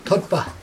[tutpa] noun skull